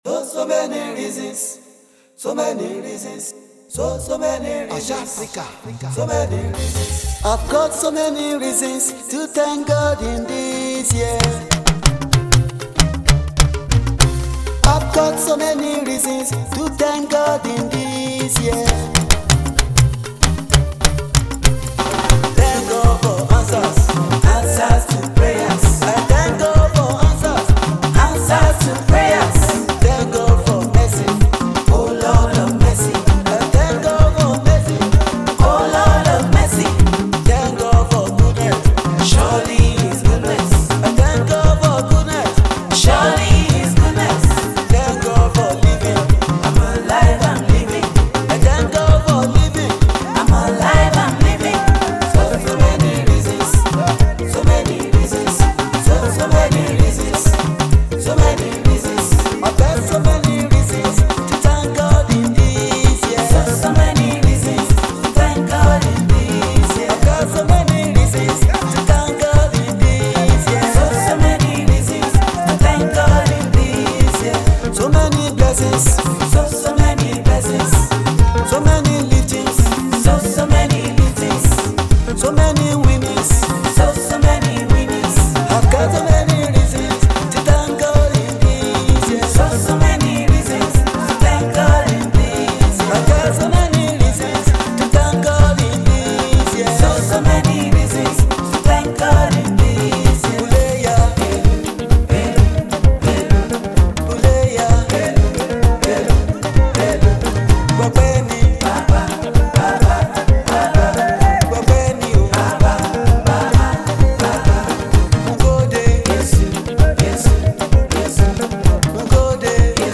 Gospel Music minister
praise song
gospel artiste with Owambe flavour